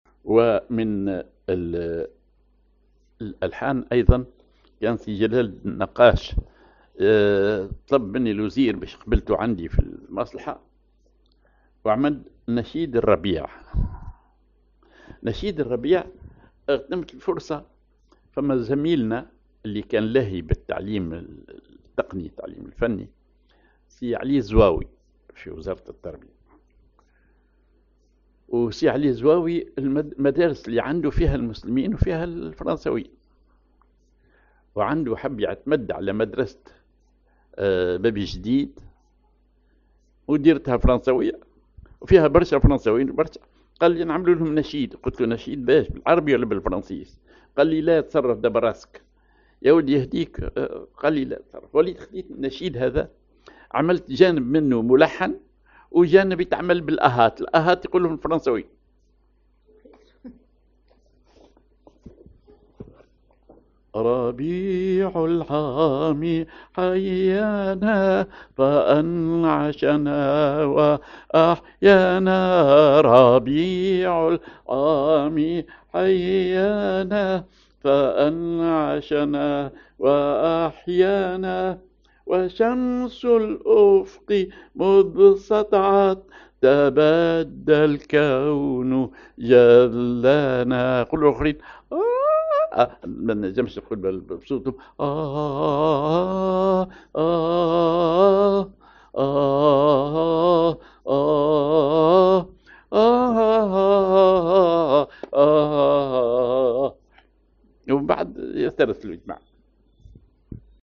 Maqam ar نهوند
Rhythm ar دارج
genre موشح